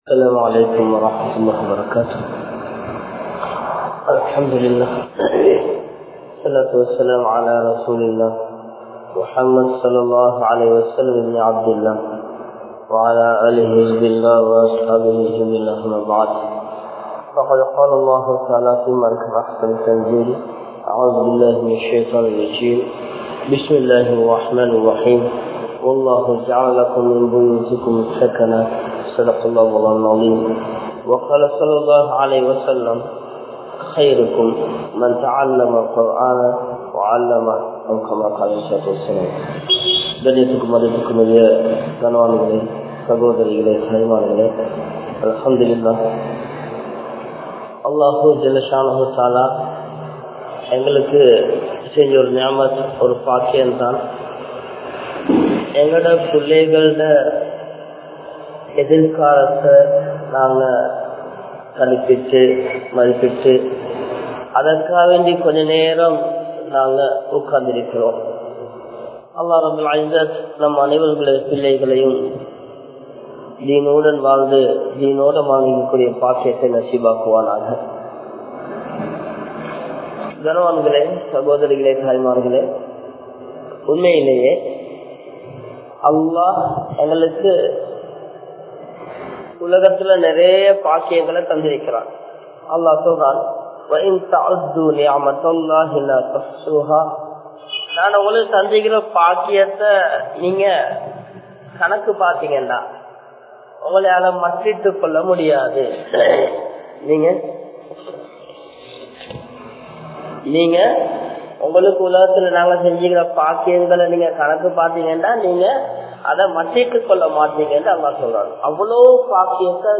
Pillaihalai Seeralippathu Indraya Pettroarhala? (பிள்ளைகளை சீரழிப்பது இன்றைய பெற்றோர்களா?) | Audio Bayans | All Ceylon Muslim Youth Community | Addalaichenai